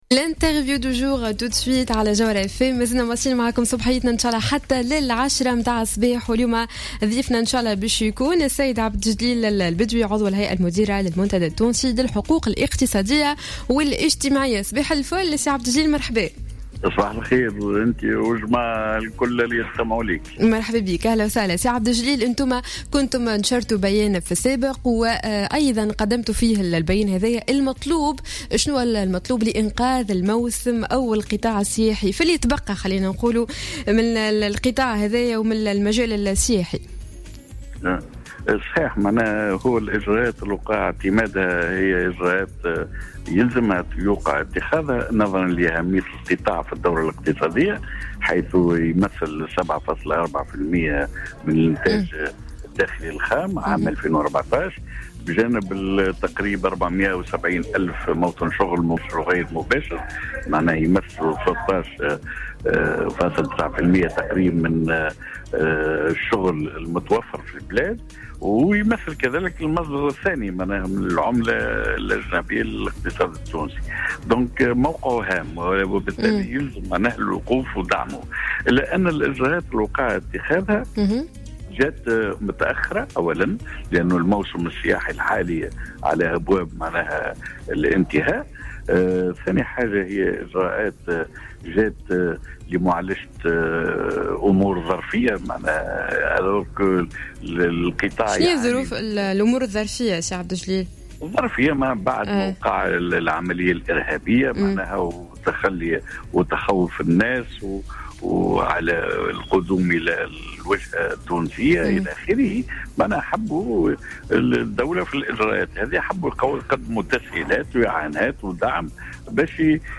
في تصريح لجوهرة أف أم